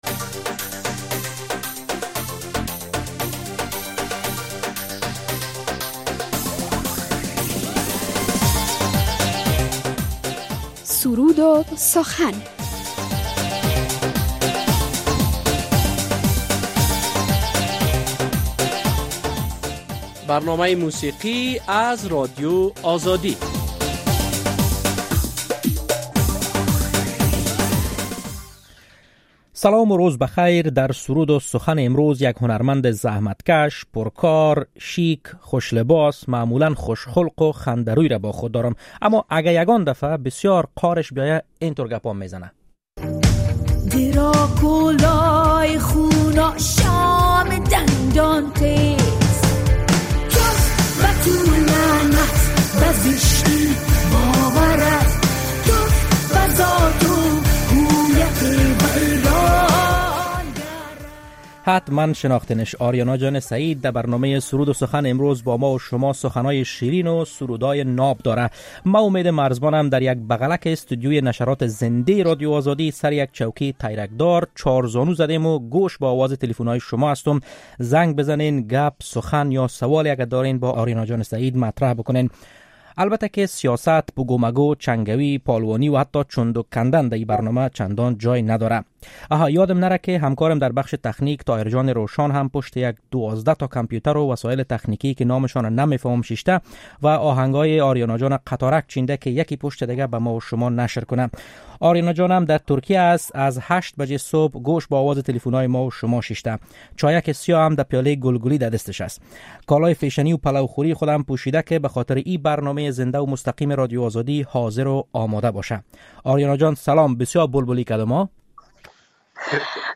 در برنامۀ سرود و سخن آریانا سعید هنرمند پر طرفدار افغان سخن‌ها و سرود ها دارد.